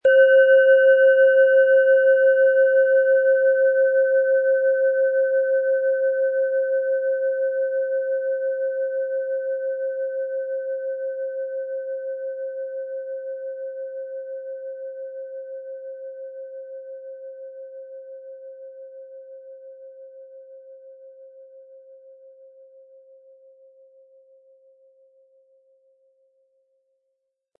Aber dann würde der ungewöhnliche Ton und das einzigartige, bewegende Schwingen der traditionellen Herstellung fehlen.
Der richtige Schlegel ist umsonst dabei, er lässt die Klangschale voll und angenehm erklingen.
HerstellungIn Handarbeit getrieben
MaterialBronze